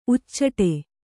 ♪ uccaṭe